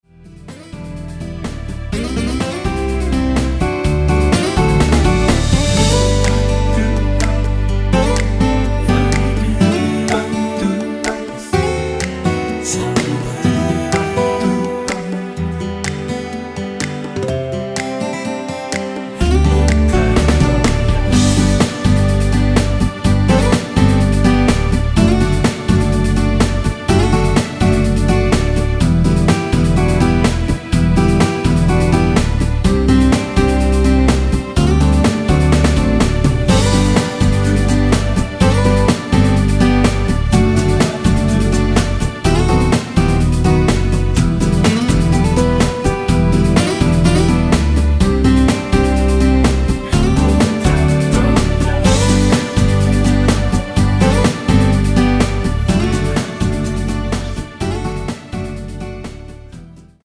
코러스 포함된 MR입니다.
앞부분30초, 뒷부분30초씩 편집해서 올려 드리고 있습니다.
중간에 음이 끈어지고 다시 나오는 이유는